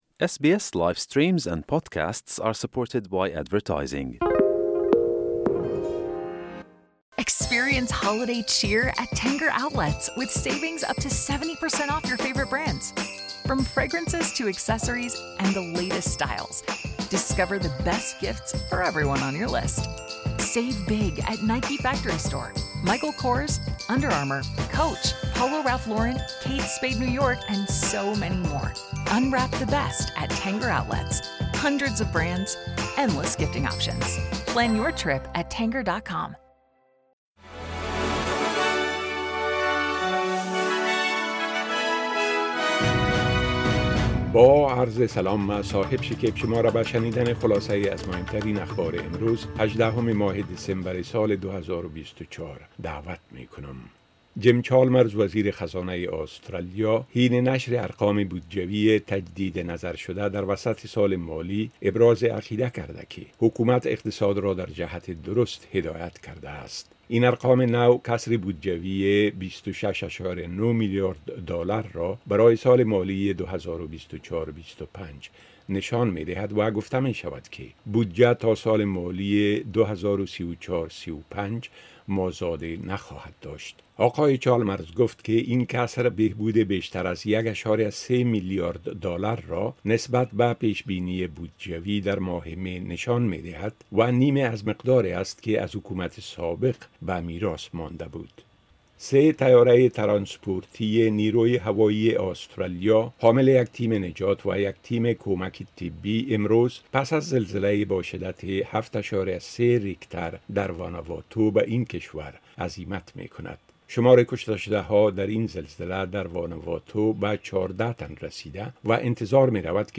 خلاصۀ اخبار مهم امروز ۱۸ دسمبر ۲۰۲۴ به زبان درى از اس بى اس را در اينجا شنيده مى توانيد.